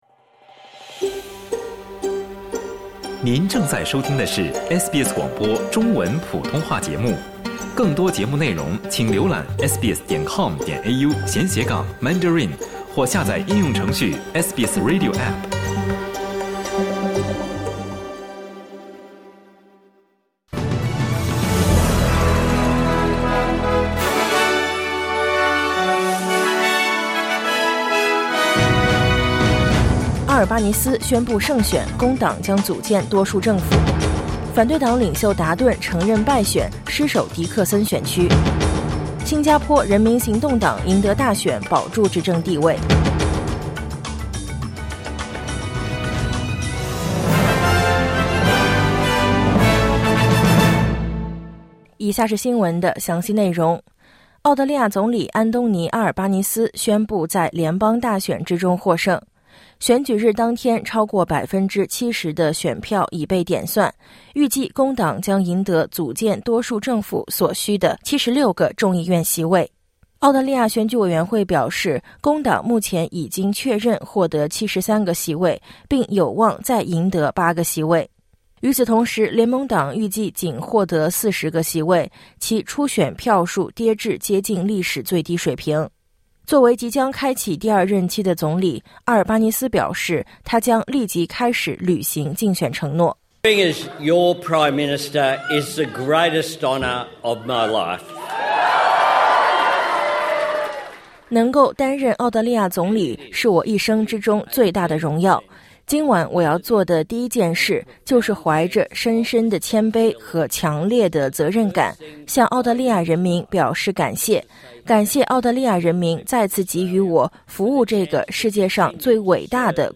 SBS早新闻（2025年5月4日）